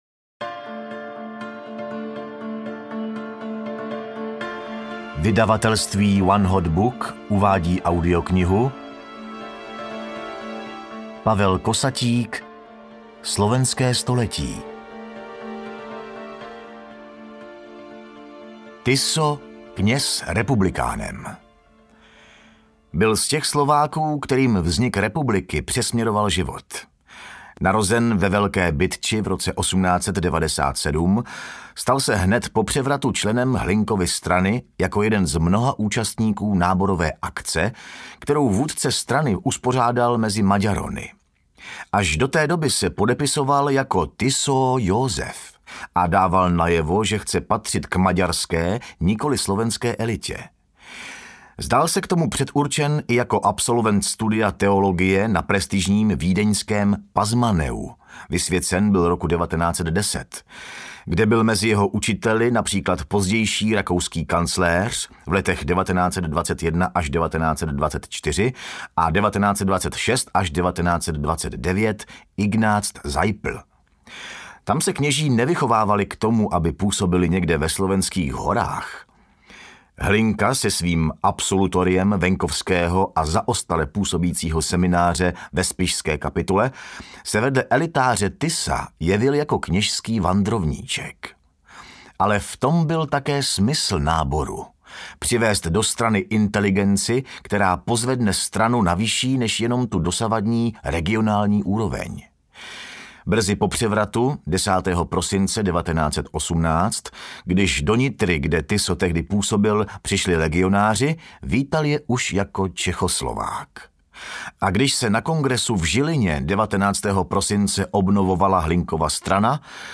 Slovenské století audiokniha
Ukázka z knihy